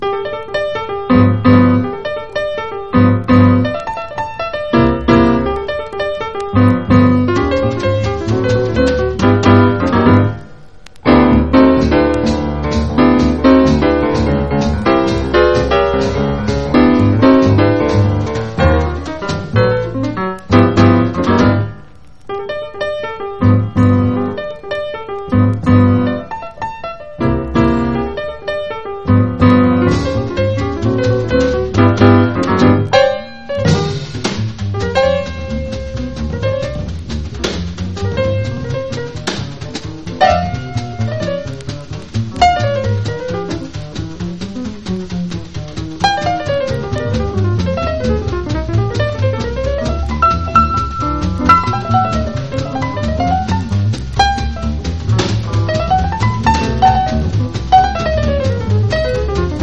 EASY LISTENING / OTHER / CHILDREN / FOLK
ウッドベース伴奏のトーキー・フォーク
バンジョー・ソロで奏でる